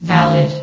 S.P.L.U.R.T-Station-13/sound/vox_fem/valid.ogg
* New & Fixed AI VOX Sound Files